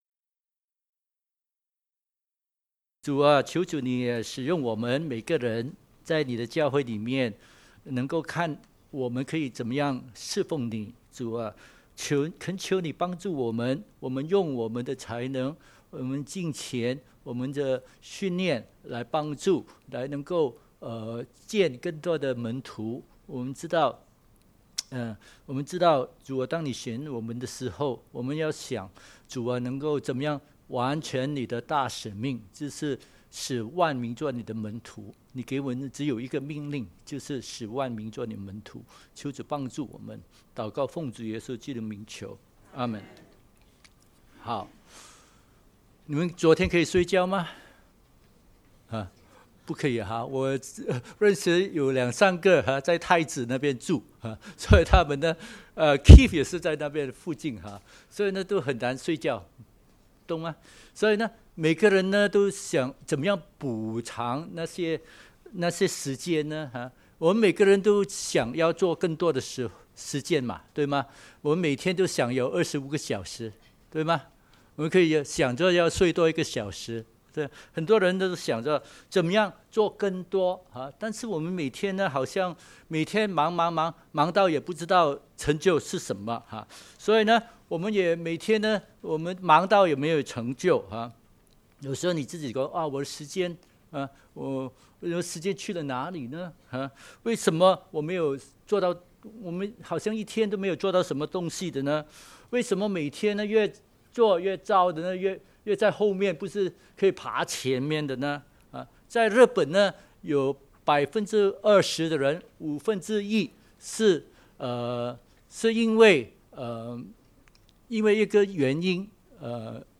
華語崇拜（荔枝角) - 使命傳承，薪火相傳
證道重溫